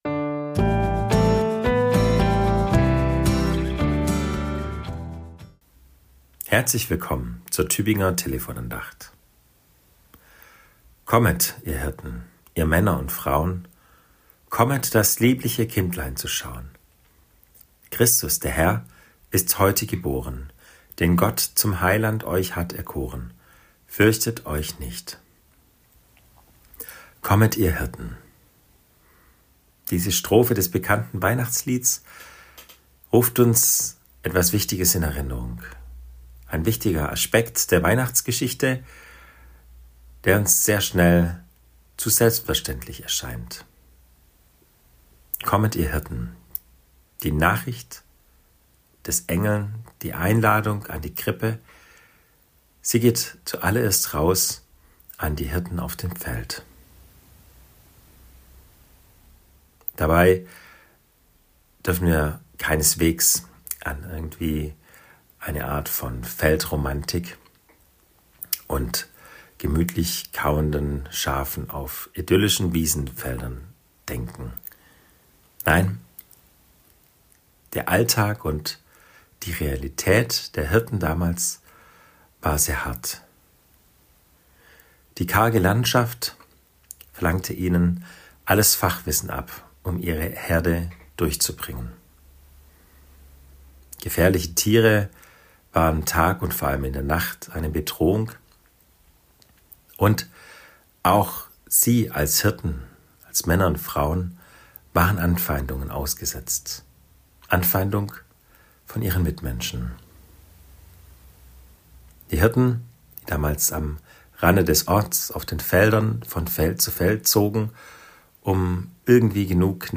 Andacht zur Weihnachtswoche